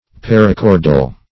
Search Result for " parachordal" : The Collaborative International Dictionary of English v.0.48: Parachordal \Par`a*chor"dal\ (p[a^]r`[.a]*k[^o]r"dal), a. [Pref. para- + chordal.]